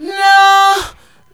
NOO VOX.wav